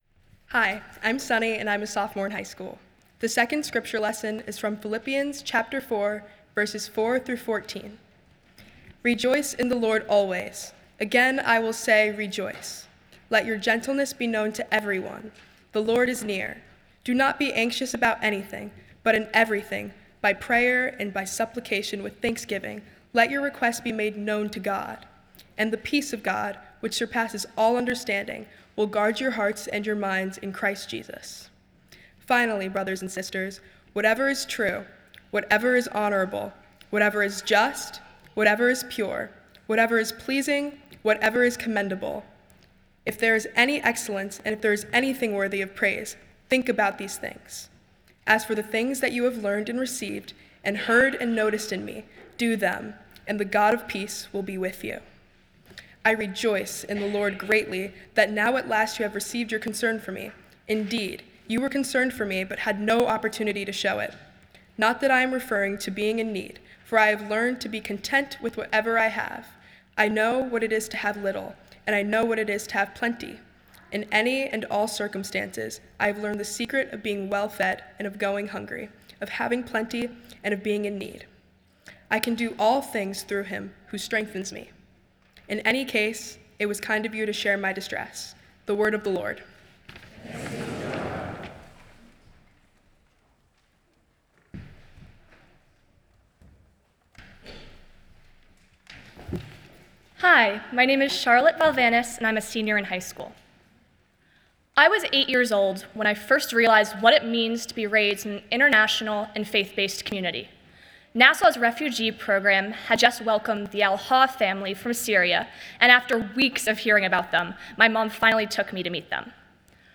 Nassau's Youth preaches on Philippians 4:4-8. Youth Sunday, Fourth Sunday in Lent, March 22, 2026.